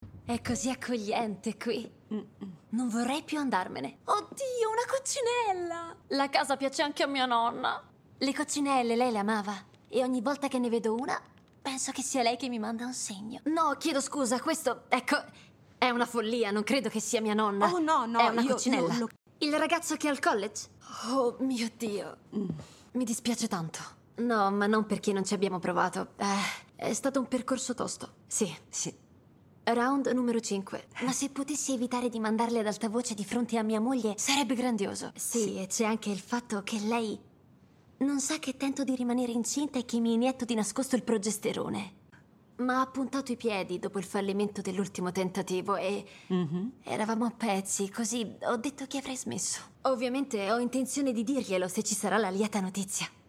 nel telefilm "No Good Deed", in cui doppia Poppy Liu.